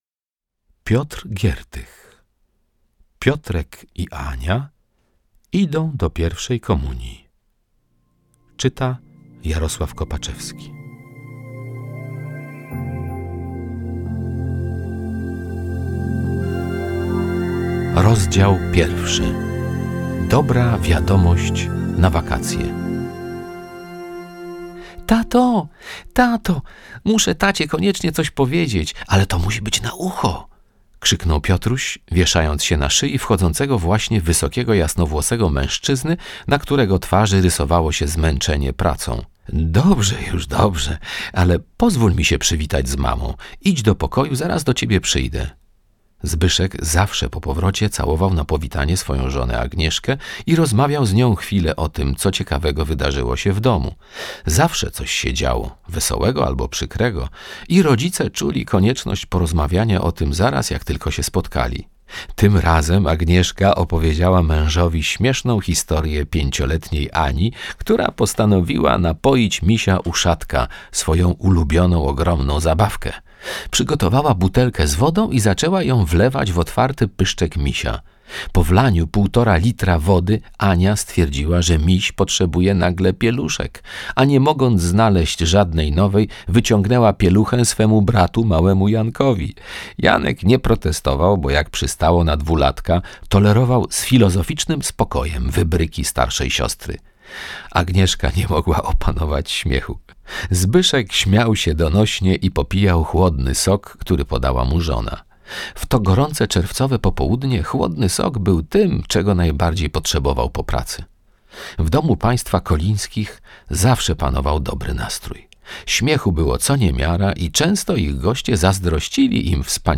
Książka dostępna również w formie z pierwszego wydania w postaci audioobooka MP3.